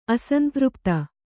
♪ asantřpta